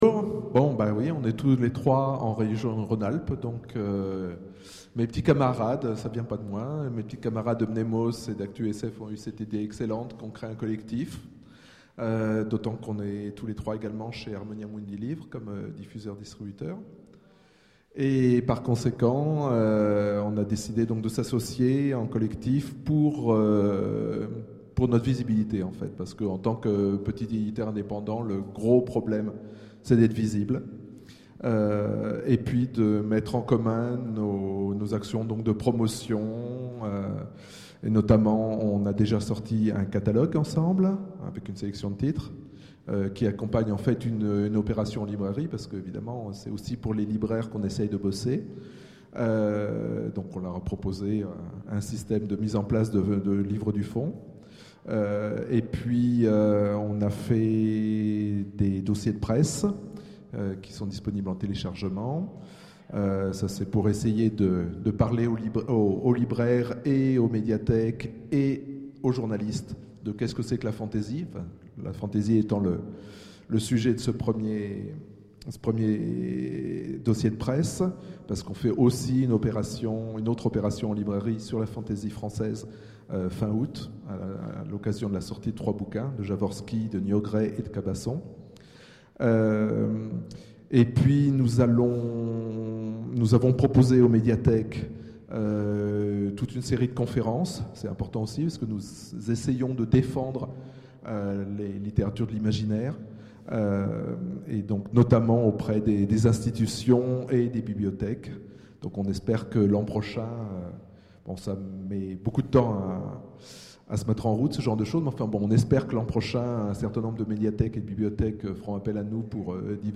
Les Indés de l’Imaginaire, le discours